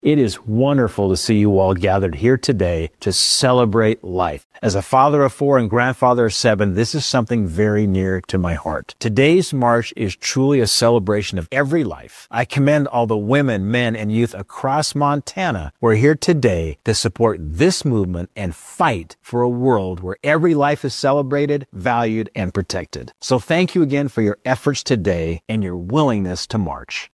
Earlier today, the Montana State Capitol in Helena was filled with hundreds of pro-life advocates for the annual Montana March for Life. Senator Steve Daines, founder of the Senate Pro-Life Caucus, addressed the crowd in the Rotunda, emphasizing the importance of protecting the sanctity of life.
Steve-Daines-march-for-life.mp3